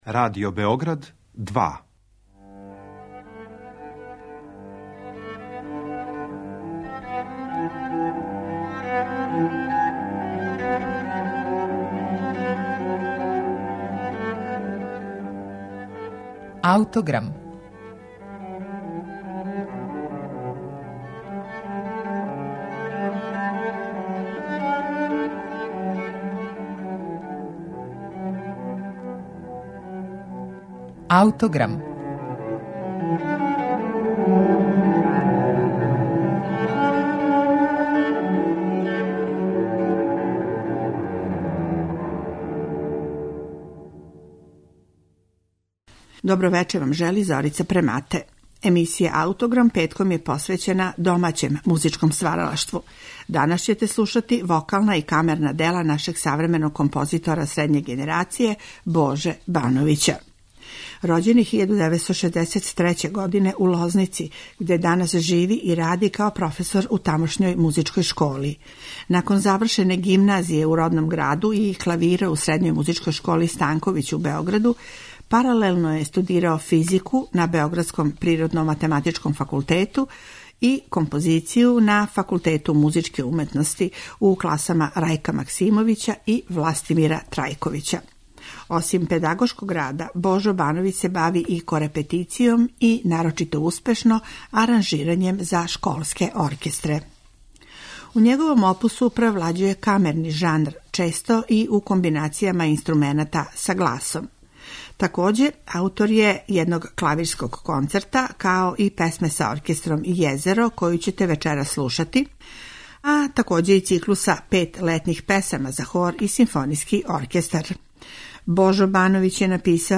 za sopran i orkestar